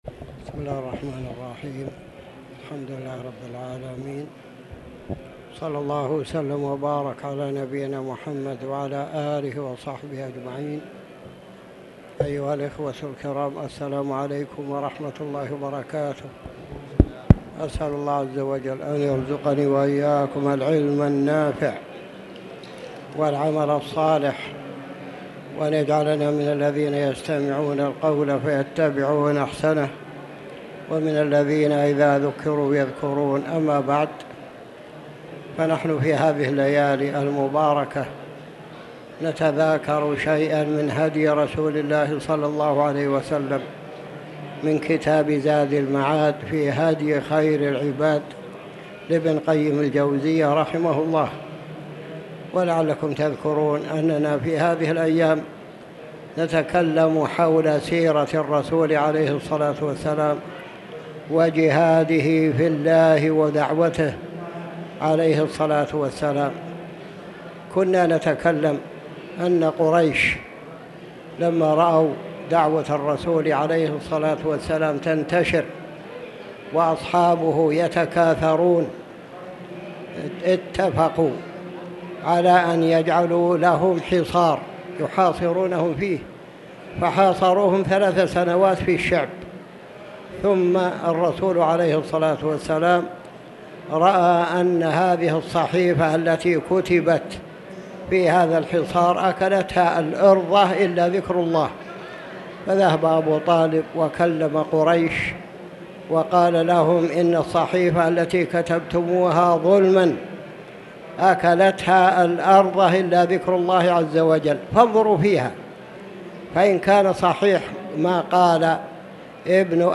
تاريخ النشر ٢٥ ذو الحجة ١٤٤٠ هـ المكان: المسجد الحرام الشيخ